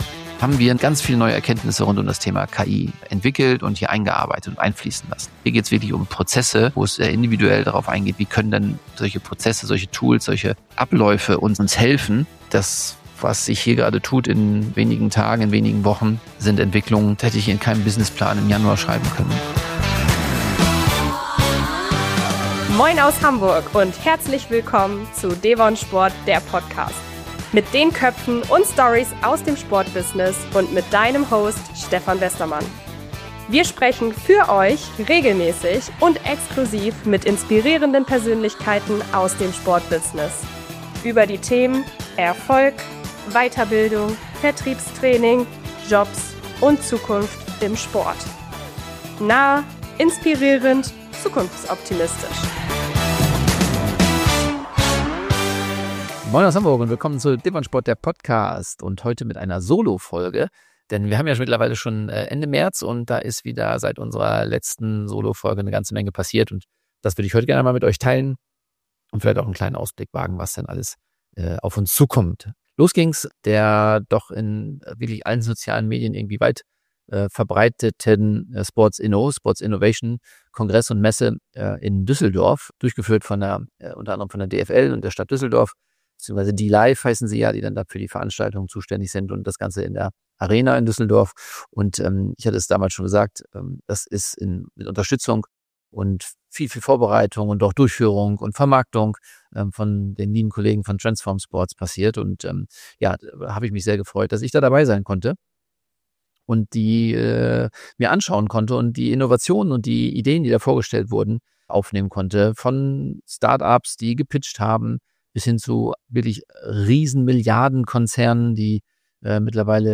Genau darüber spreche ich in dieser Solo-Folge – und nehme dich mit in die letzten, extrem intensiven Wochen bei devonSPORT....